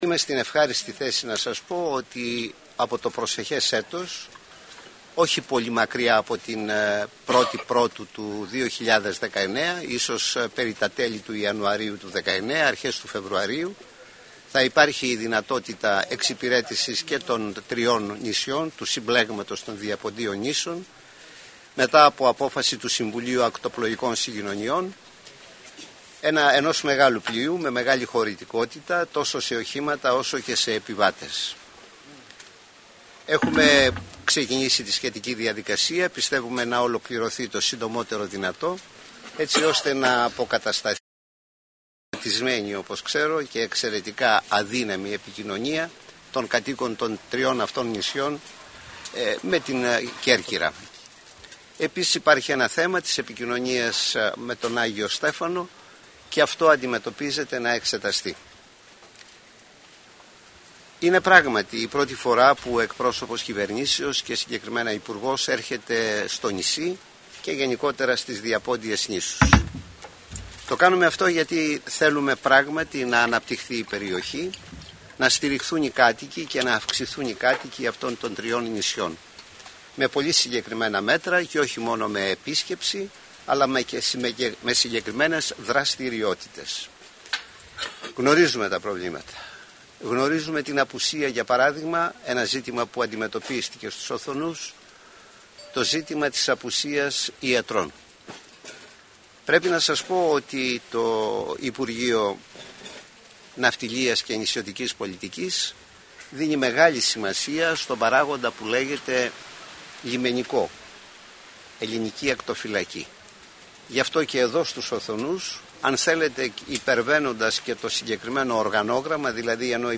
ρεπορτάζ